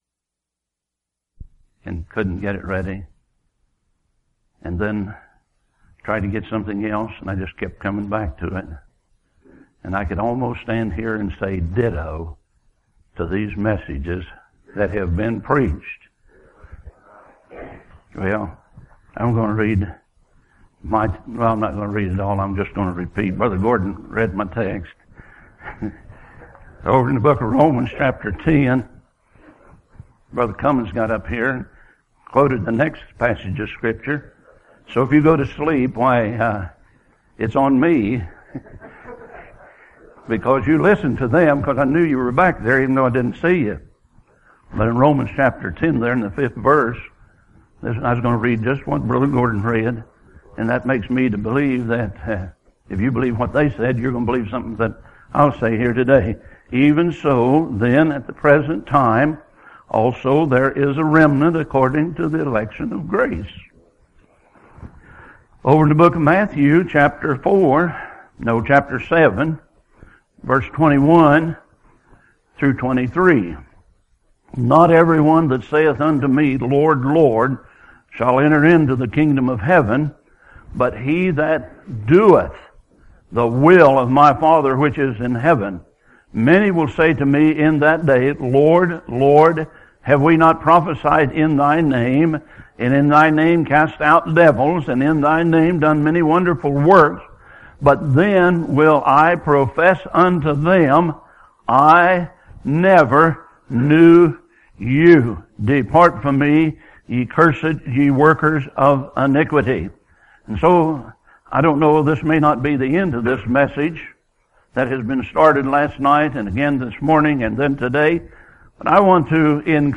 Recorded at Home Missionary Baptist Church, April 2002